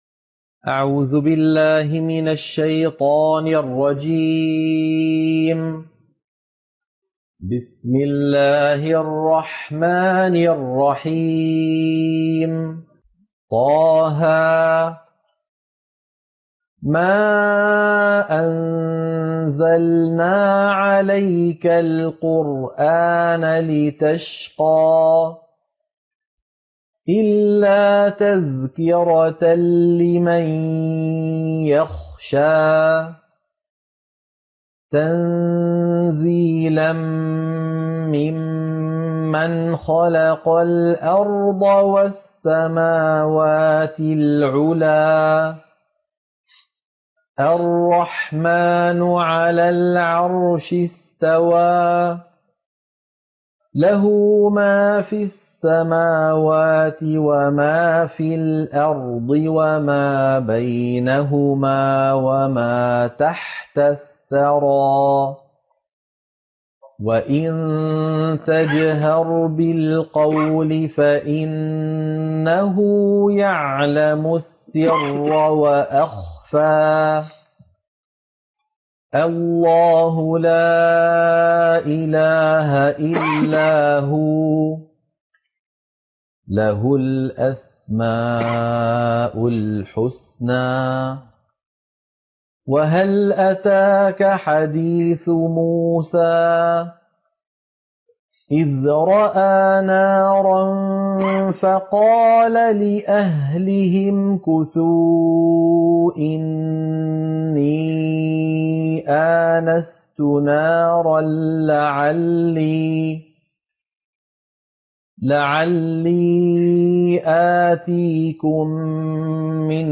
سورة طه - القراءة المنهجية